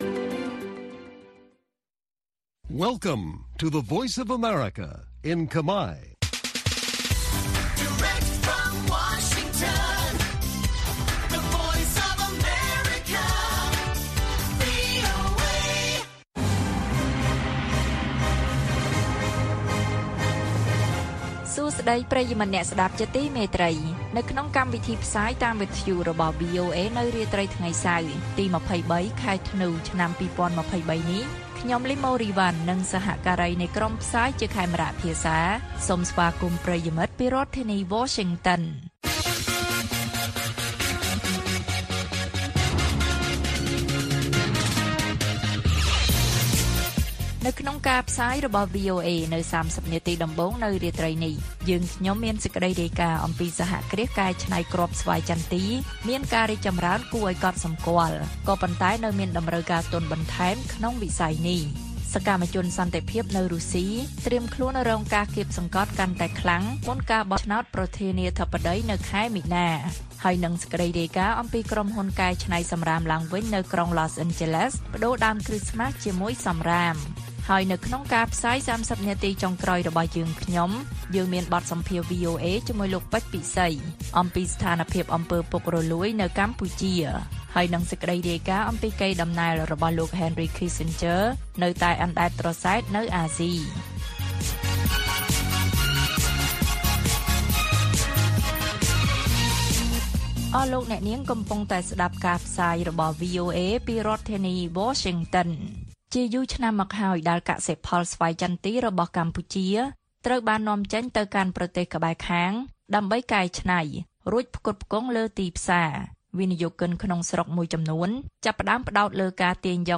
ព័ត៌មានពេលរាត្រី ២៣ ធ្នូ៖ សហគ្រាសកែច្នៃគ្រាប់ស្វាយចន្ទីមានការរីកចម្រើនគួរឱ្យកត់សម្គាល់ ប៉ុន្តែនៅមានតម្រូវការទុនបន្ថែម
បទសម្ភាសន៍ VOA